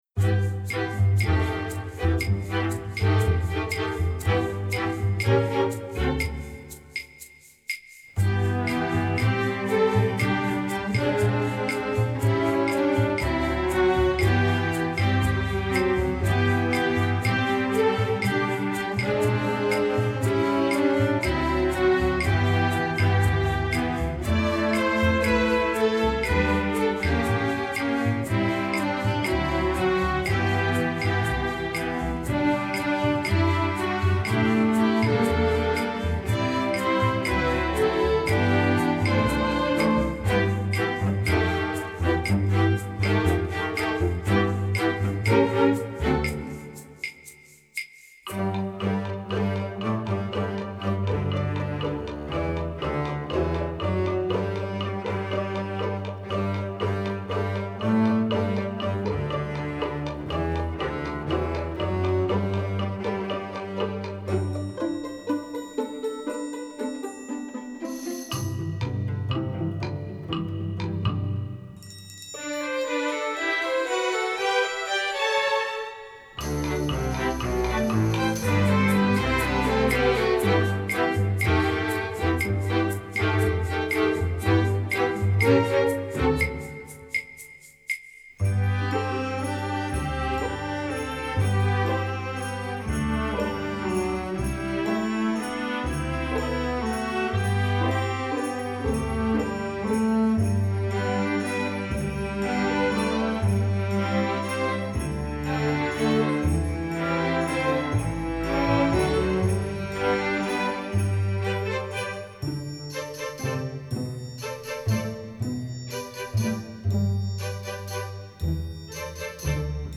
Instrumentation: string orchestra
instructional, children